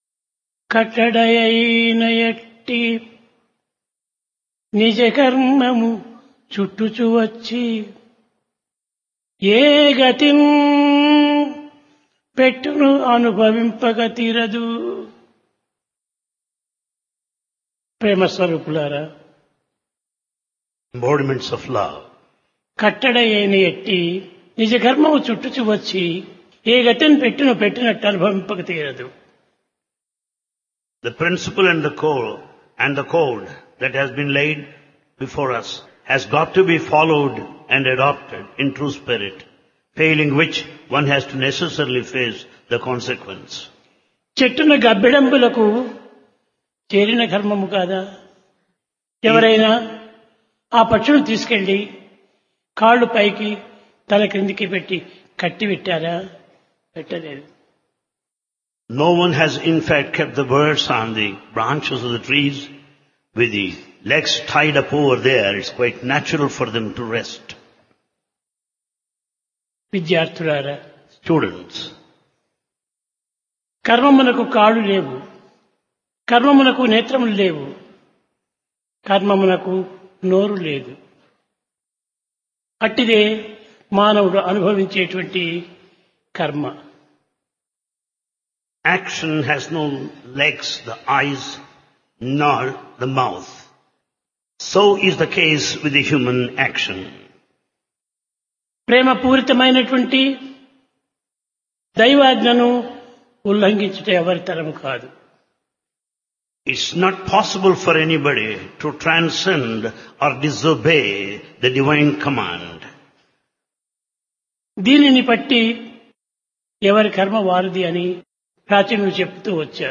Divine Discourse of Bhagawan Sri Sathya Sai Baba
Place Prasanthi Nilayam Occasion Dasara